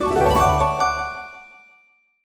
LevelCompleteSound.wav